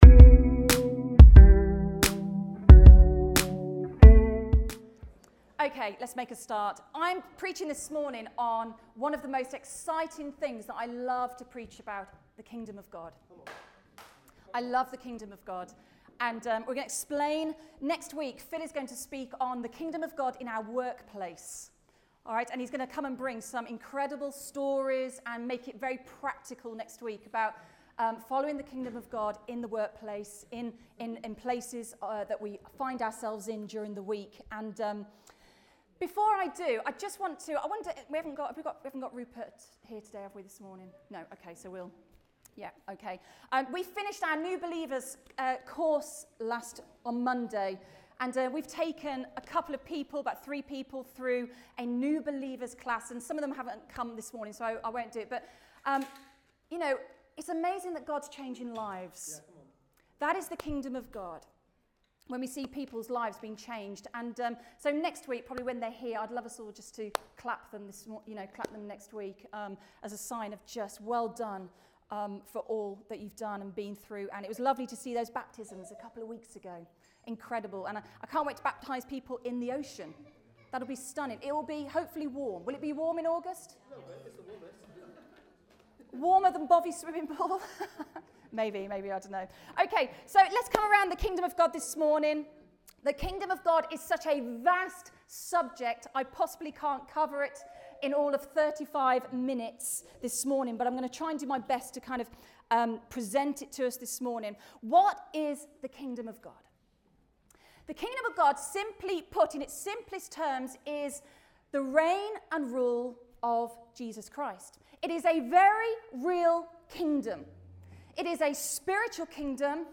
Rediscover Church Newton Abbot | Sunday Messages A Discipleship Series - Part 7 | Kingdom of God 1 | Is Jesus King?